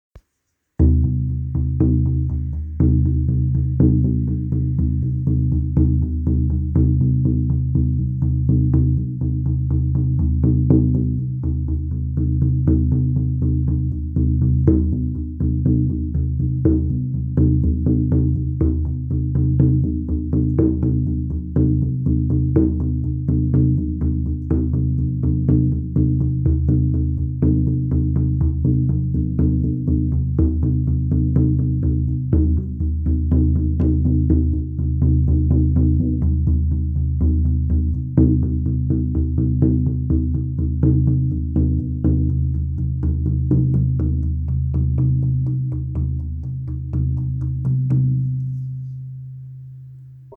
Sjamanendrum: Afrikaanse Geit op Essenhout, 45 cm – WIT
De drum klinkt vol en krachtig en geeft een rijk veld van boventonen, waar de Afrikaanse geit zo om bekend staat.